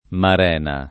vai all'elenco alfabetico delle voci ingrandisci il carattere 100% rimpicciolisci il carattere stampa invia tramite posta elettronica codividi su Facebook amarena [ amar $ na ] (pop. marena [ mar $ na ]) s. f. — sim. il cogn.